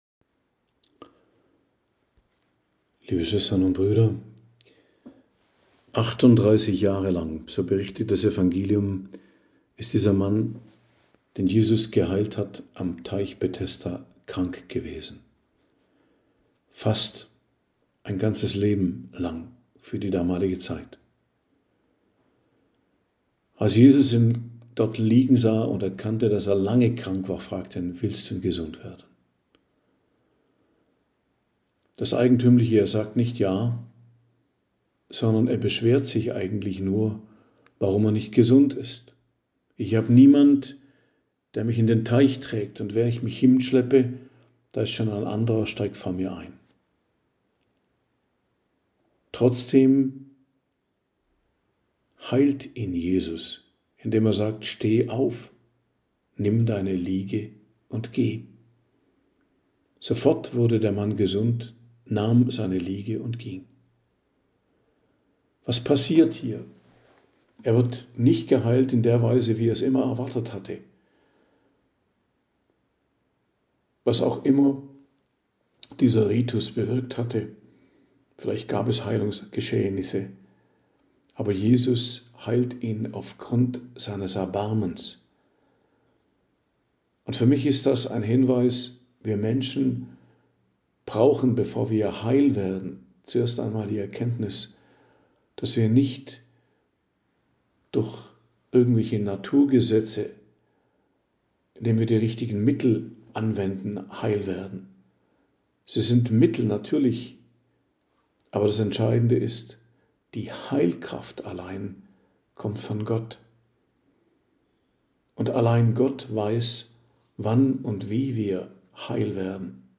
Predigt am Dienstag der 4. Woche der Fastenzeit, 1.04.2025